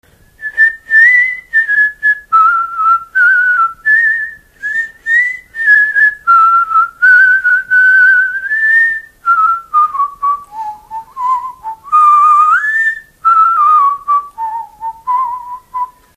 Dallampélda: Hangszeres felvétel
fütty Műfaj: Szapora Gyűjtő